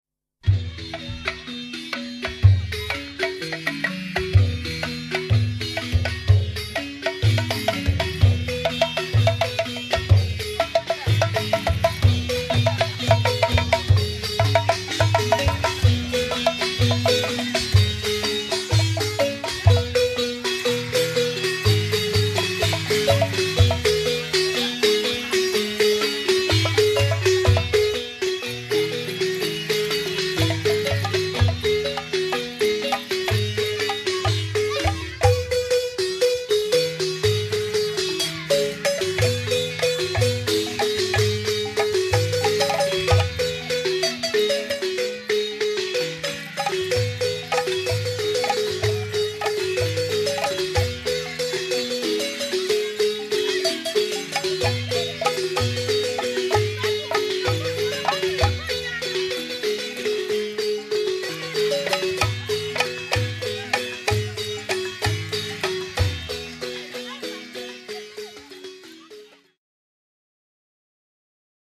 Le balafon
Orchestre de xylophone jegele
857-Xylophone-jegele-C¦te-dIvoire-.mp3